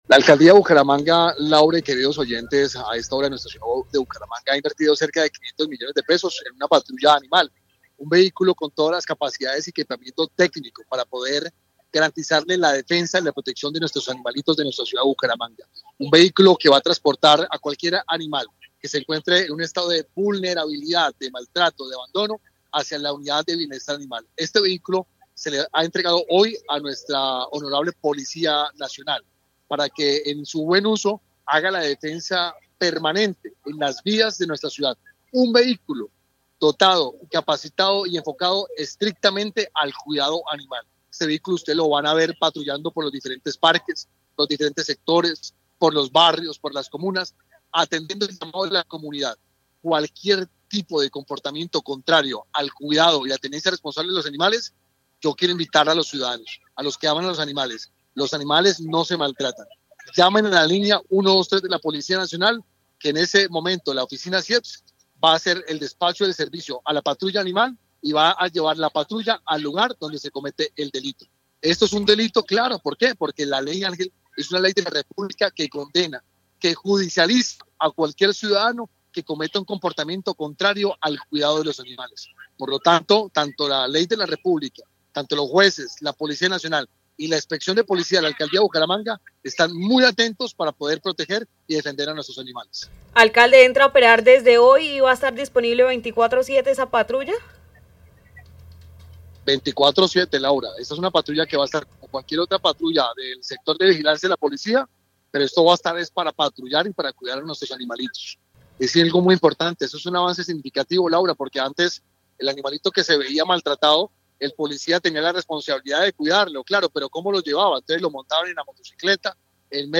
Cristian Portilla, alcalde de Bucaramanga
Según explicó el alcalde de Bucaramanga, Cristian Portilla, en Caracol Radio, el automotor cuenta con equipamiento técnico adecuado para el traslado digno de los animales hacia la Unidad de Bienestar Animal y fue entregado oficialmente a la Policía Nacional, entidad que estará a cargo de su operación.